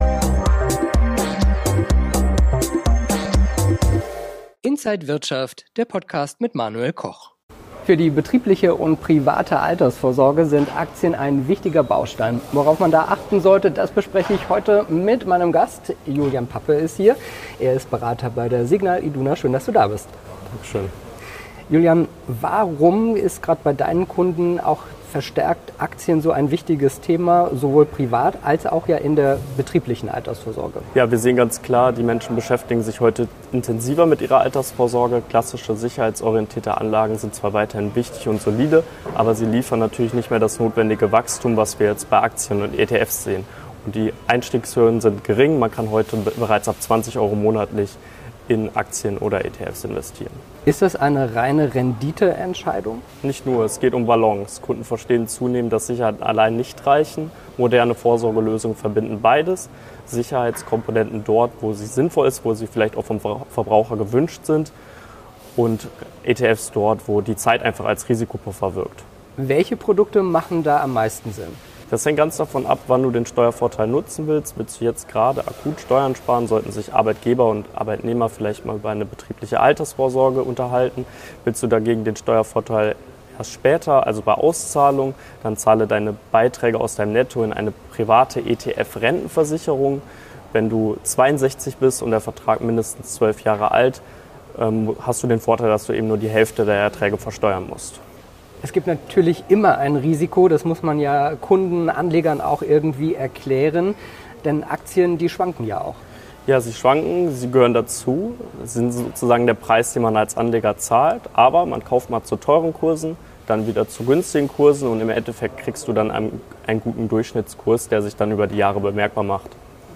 Alle Details im Interview von Inside